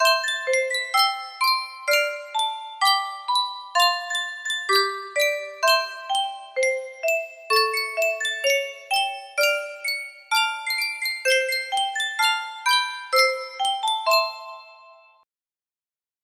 Sankyo Music Box - Give My Regards to Broadway CjG music box melody
Full range 60